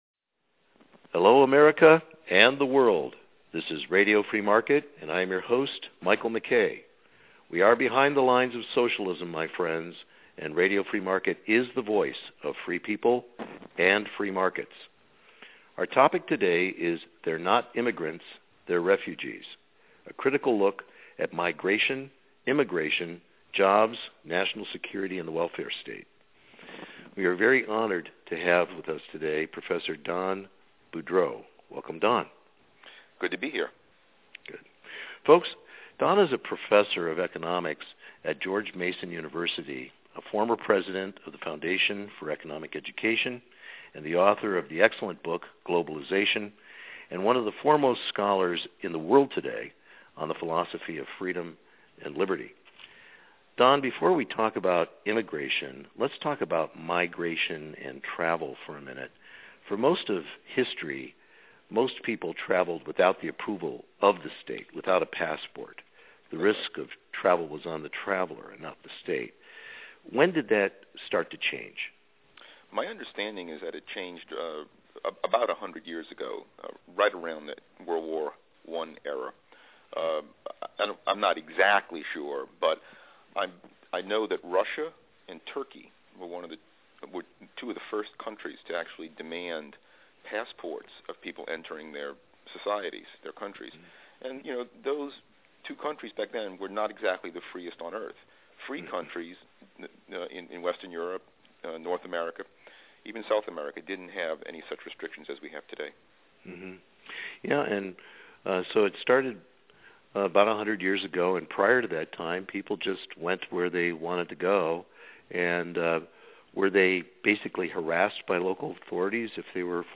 An Exclusive Interview